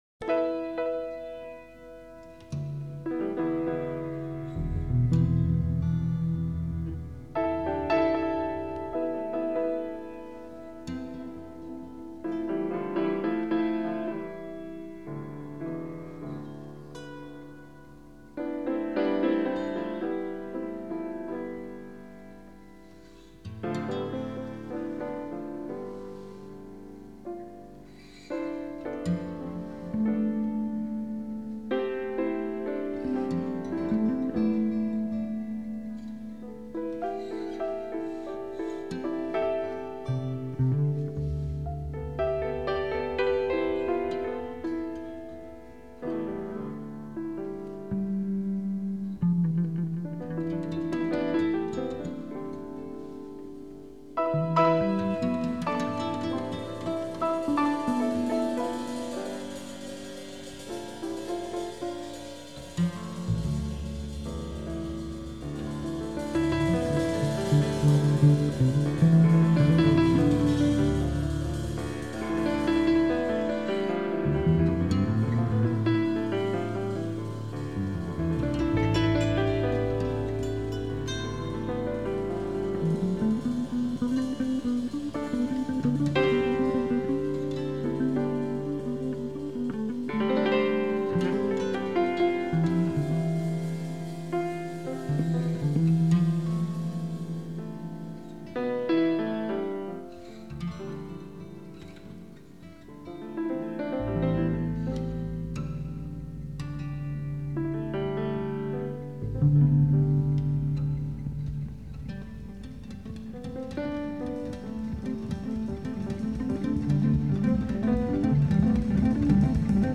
a locked-in and whacked-out spacey instrumental
piano
stand-up bass